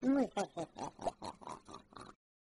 Muhehehehe Sound Effect Download: Instant Soundboard Button